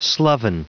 Prononciation du mot sloven en anglais (fichier audio)
Prononciation du mot : sloven